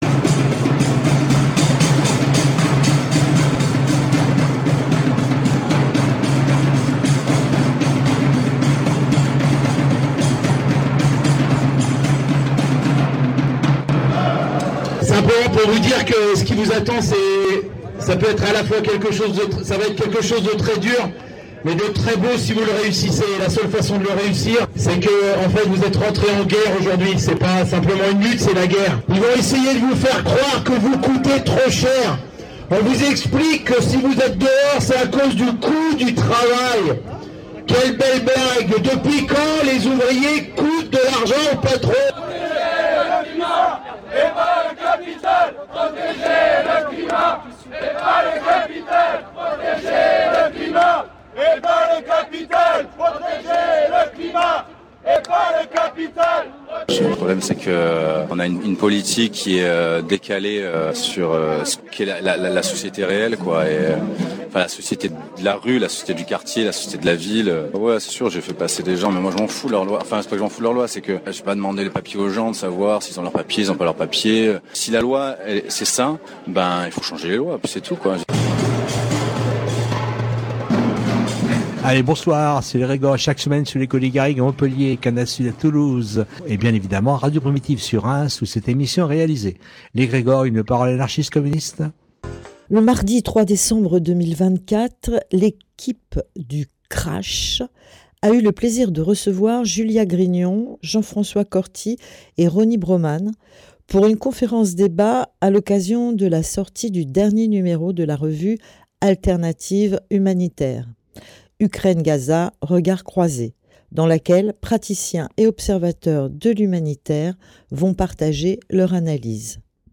Dans l’émission de ce jour, nous vous proposons l’écoute d’une partie de la conférence-débat : Ukraine-Gaza, regards croisés.